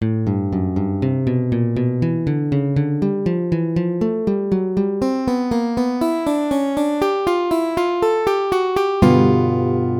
ドミナント（7th）コード
まず最初に基本となる運指です。